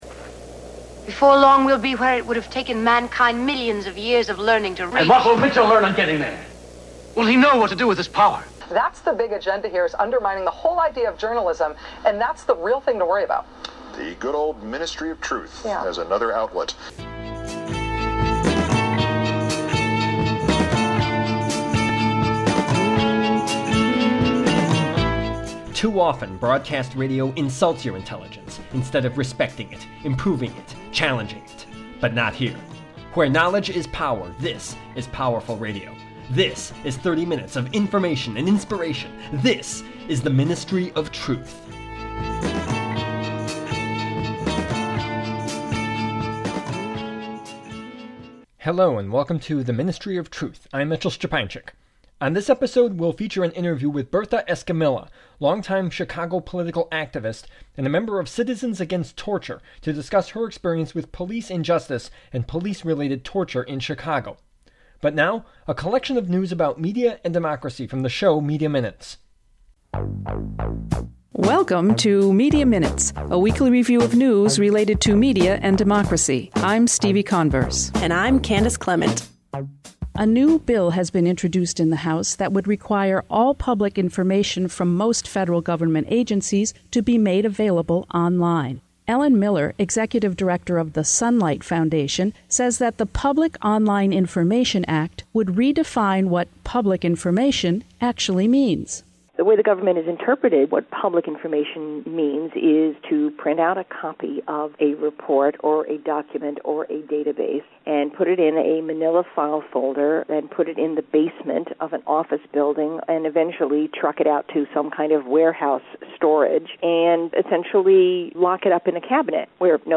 The Ministry of Truth: Interview